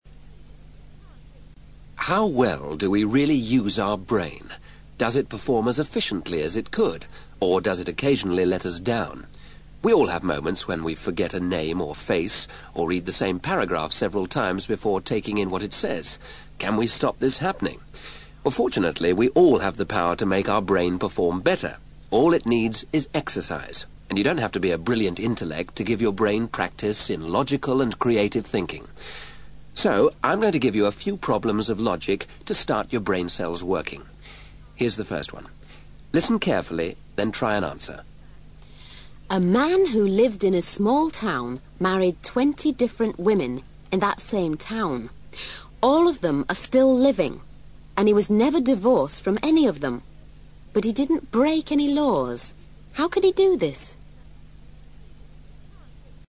ACTIVITY 311: Now, listen to a psychologist giving you the first problem in logical thinking.